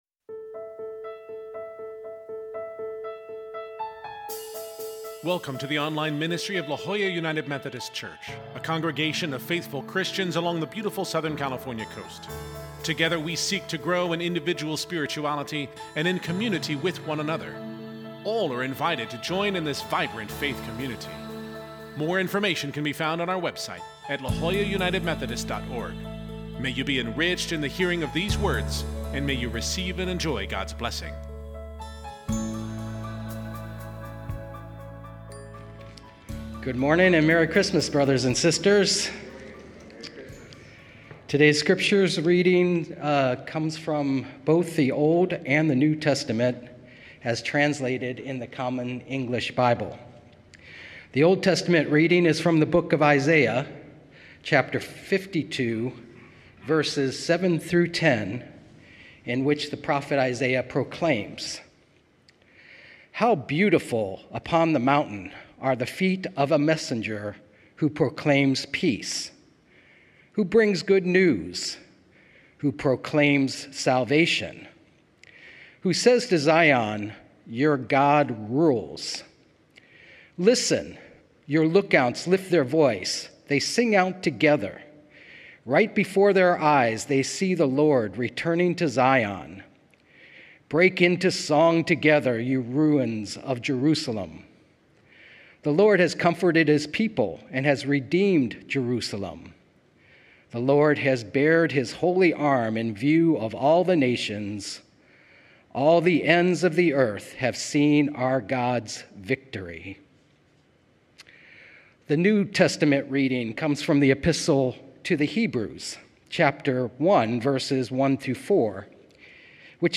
We gather for a casual service with many of our in-person participants wearing favorite Christmas garb as we sing Christmas carols and enjoy the La Jolla community of faith!